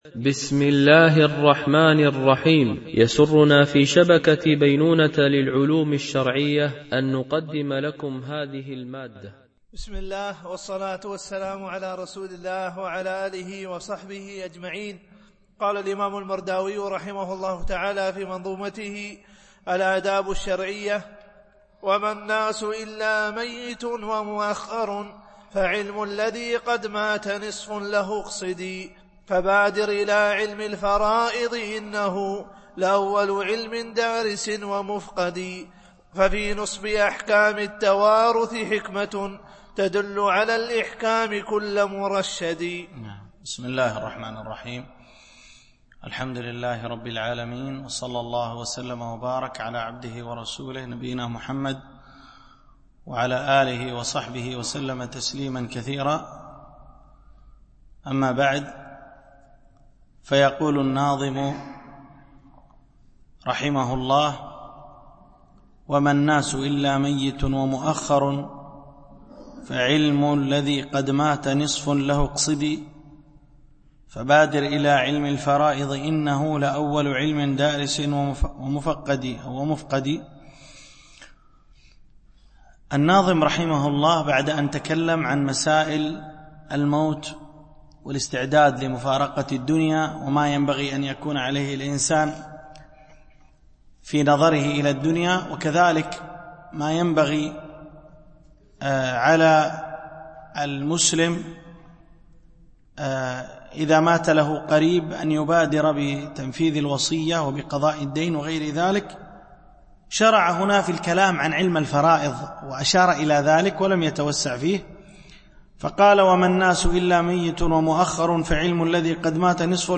شرح منظومة الآداب الشرعية – الدرس18 ( الأبيات 234-254 )